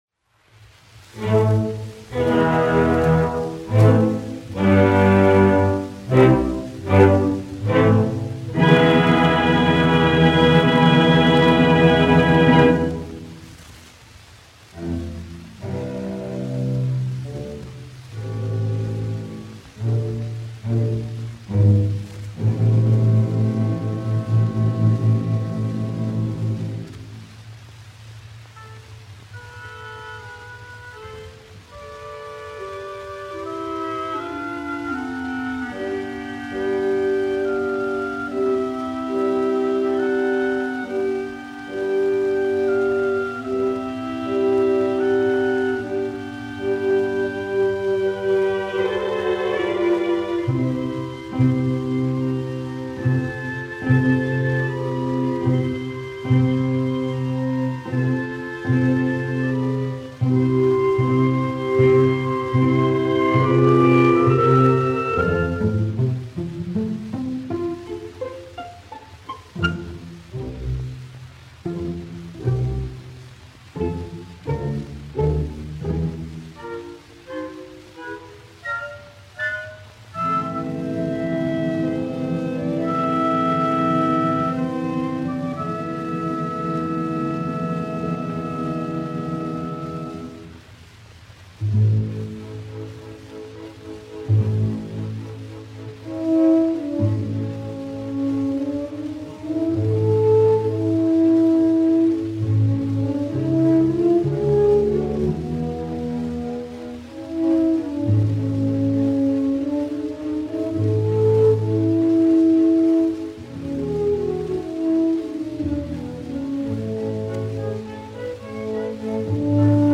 Symphonisches Orchester Berlin, izpildītājs
1 skpl. : analogs, 78 apgr/min, mono ; 25 cm
Uvertīras
Latvijas vēsturiskie šellaka skaņuplašu ieraksti (Kolekcija)